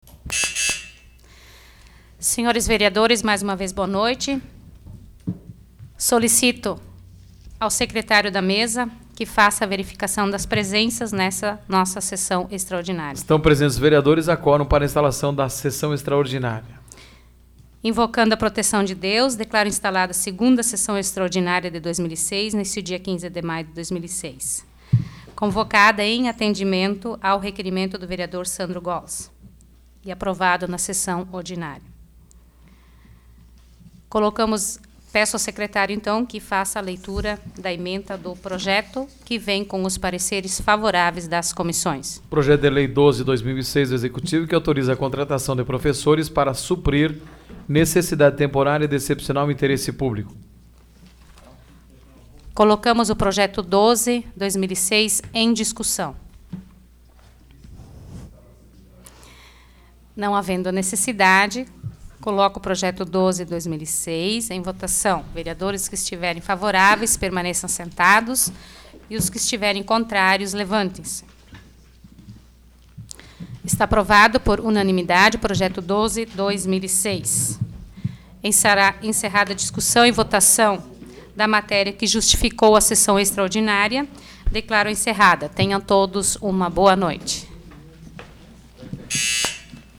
Áudio da 17ª Sessão Plenária Extraordinária da 12ª Legislatura, de 15 de maio de 2066